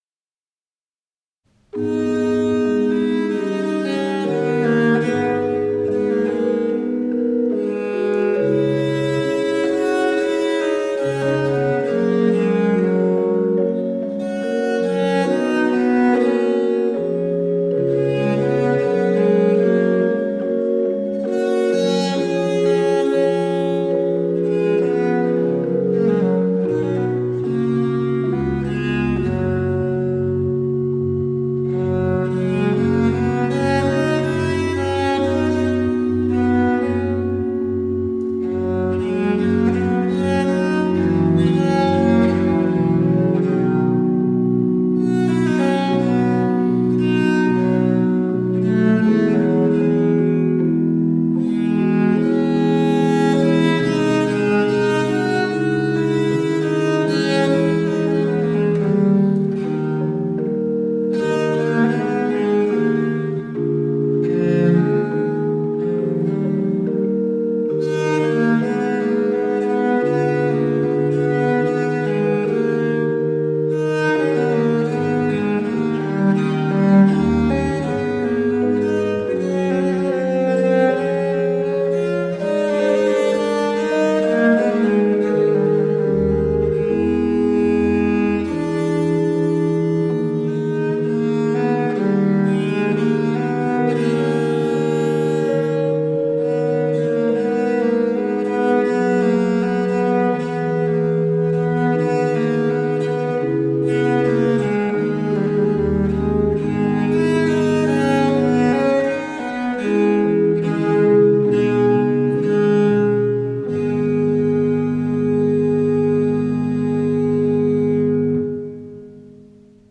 German Sonatas for Viola da Gamba from the Baroque period.